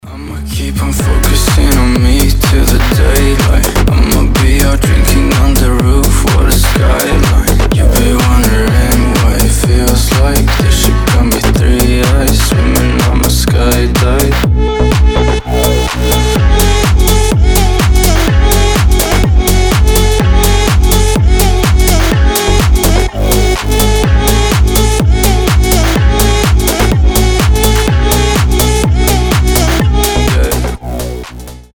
• Качество: 320, Stereo
house
ремиксы
Прикольный ремикс качёвого трека